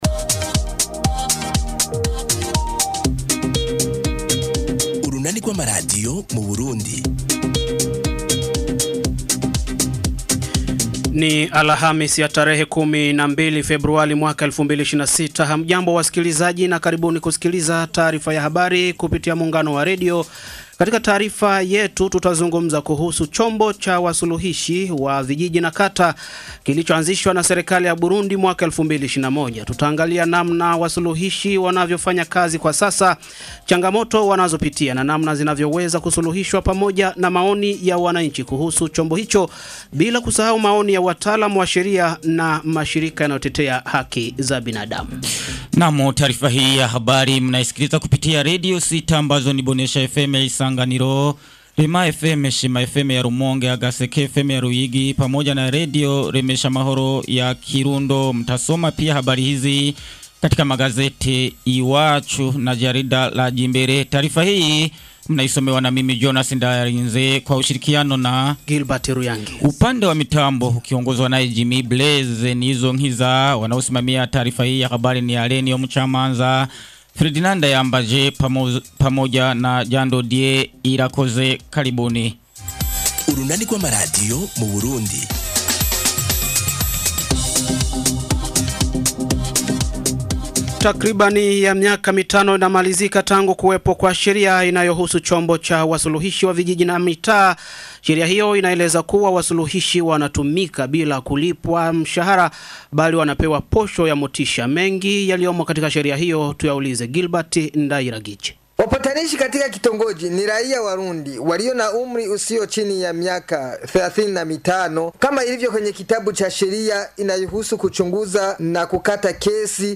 Taarifa ya habari ya muungano wa redio ya tarehe 12 Februari 2026